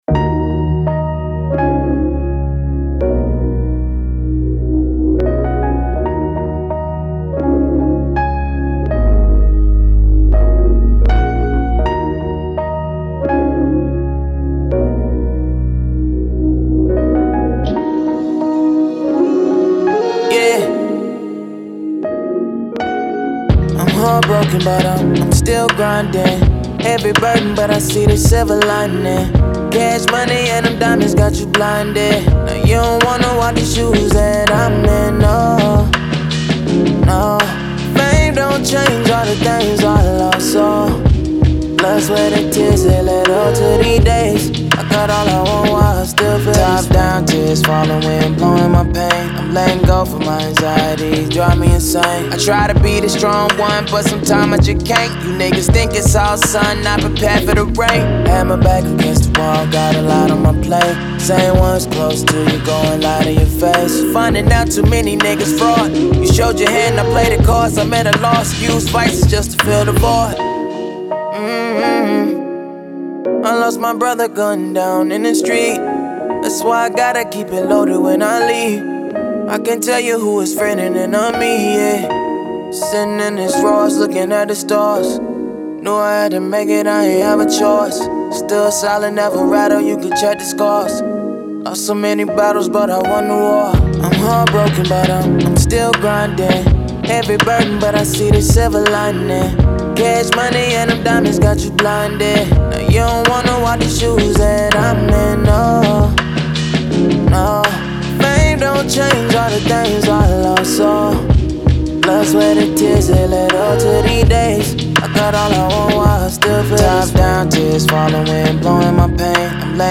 Hip Hop
E Minor